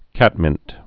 (kătmĭnt)